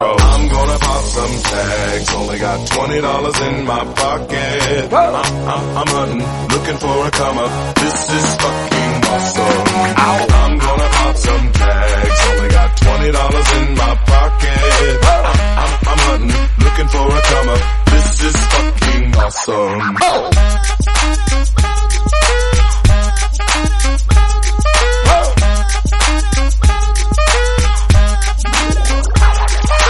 hip-hop song